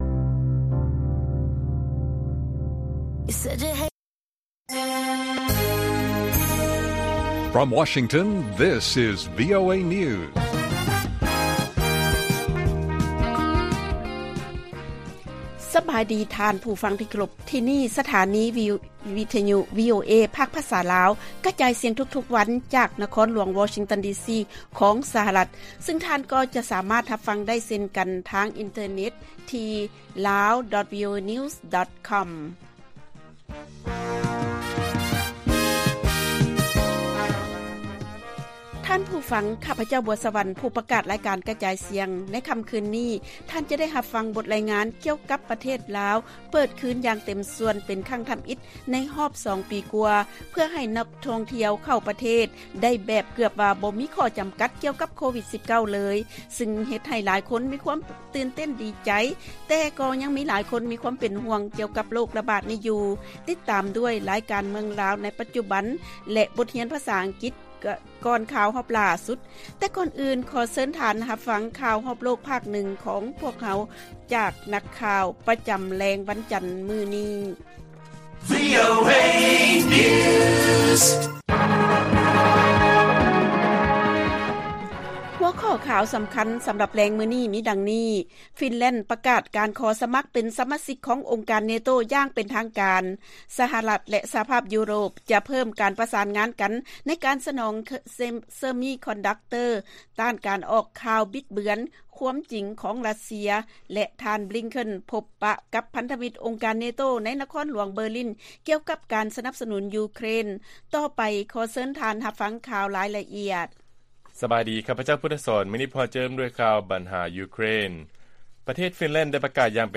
ລາຍການກະຈາຍສຽງຂອງວີໂອເອ ລາວ: ຟິນແລນ ປະກາດການຂໍສະໝັກເປັນສະມາຊິກອົງການ NATO ຢ່າງເປັນທາງການ